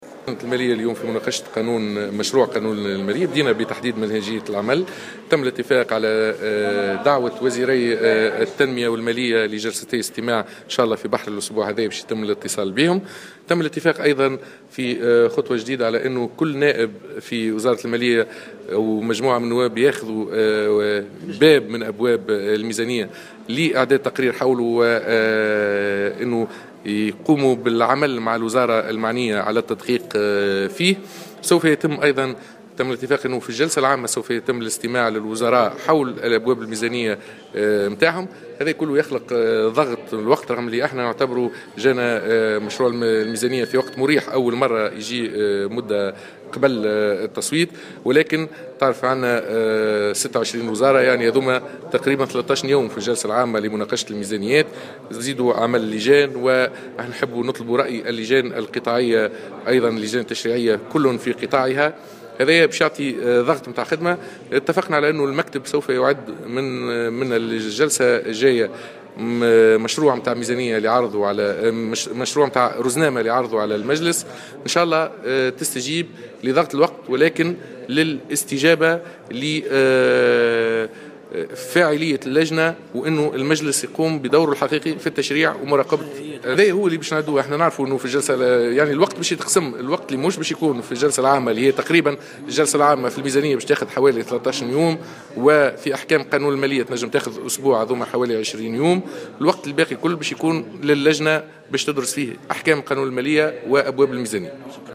وأضاف الدهماني في تصريح ل "الجوهرة أف أم" أنه تم الاتفاق أيضا على إعداد مشروع رزنامة عمل لعرضها على المجلس.